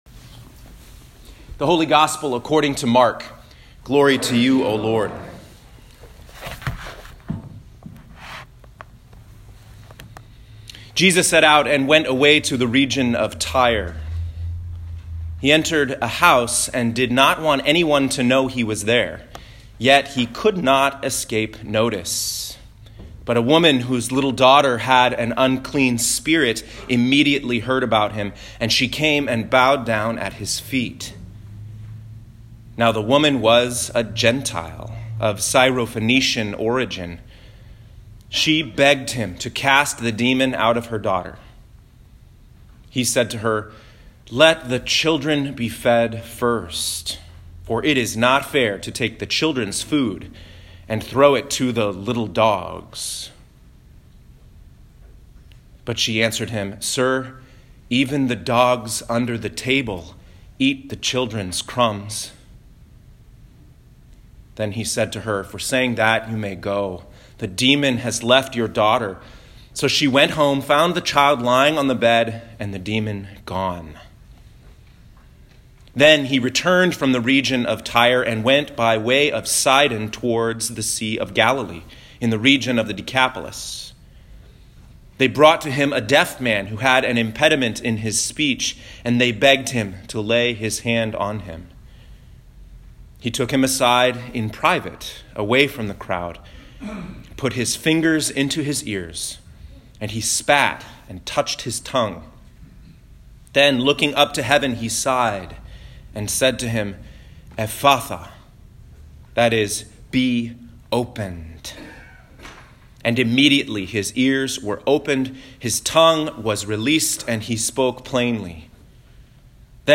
Sixteenth Sunday after Pentecost, Year B (9/9/2018) Isaiah 35:4-7a Psalm 146 James 2:1-10 [11-13] 14-17 Mark 7:24-37 Click the play button to listen to this week’s sermon.